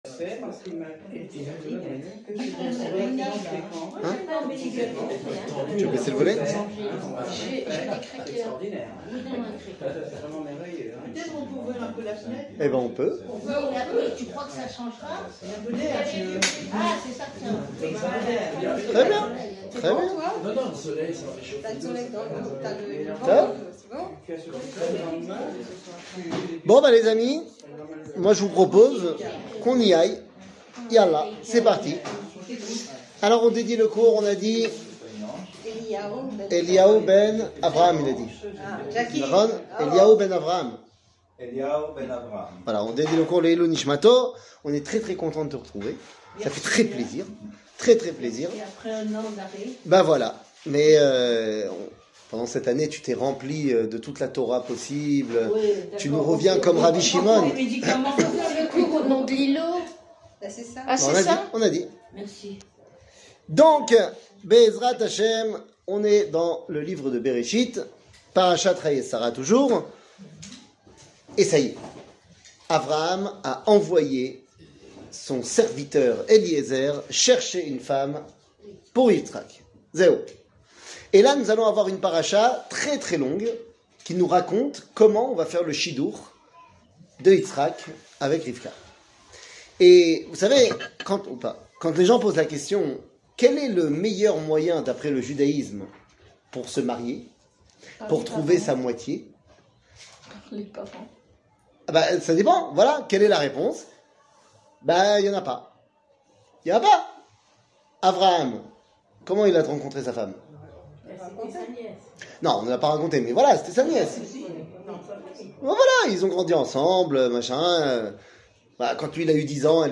קטגוריה Livre de Berechit 00:50:18 Livre de Berechit שיעור מ 18 מאי 2022 50MIN הורדה בקובץ אודיו MP3